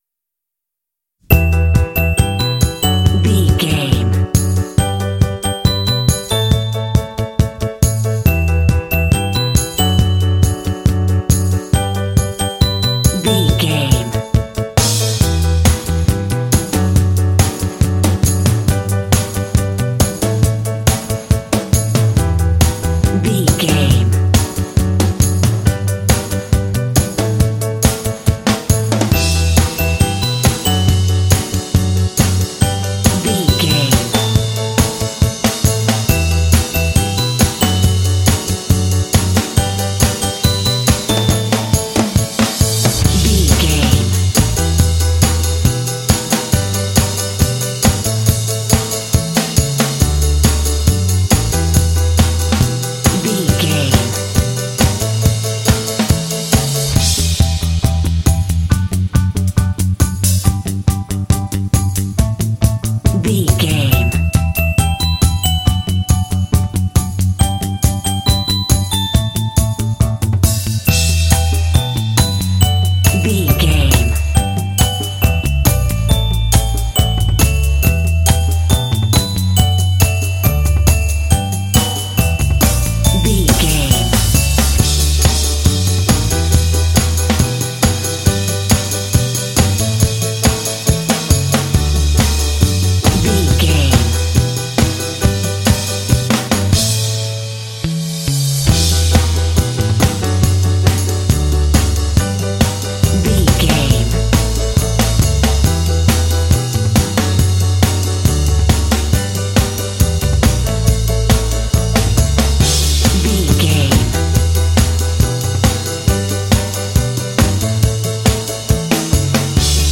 Fun and cheerful indie track with bells.
Uplifting
Ionian/Major
optimistic
bright
piano
bass guitar
percussion
pop
symphonic rock